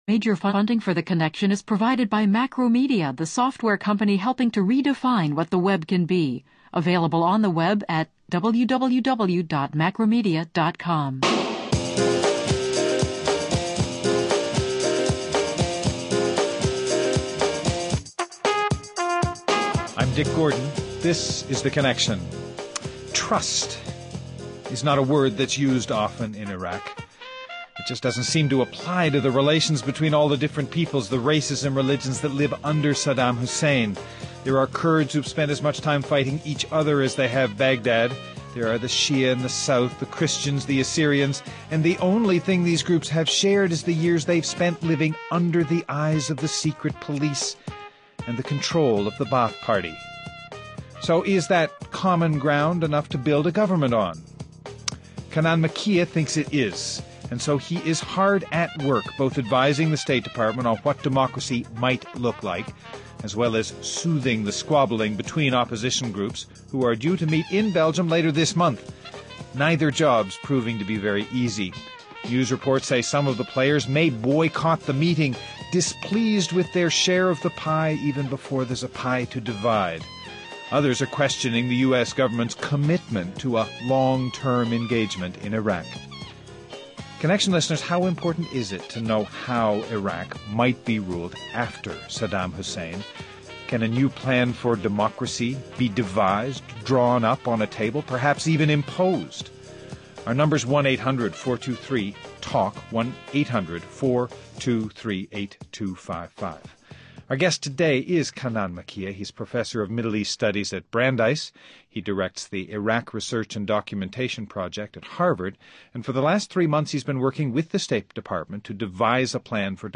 Guests: Kanan Makiya, professor of Middle East Studies at Brandeis and director of the Iraq Research and Documentation Project at Harvard University.